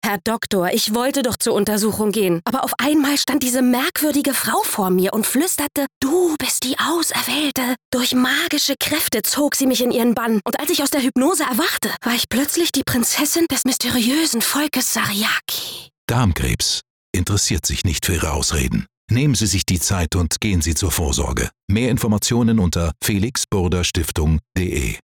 Radio Ad (DE) "Sariaki"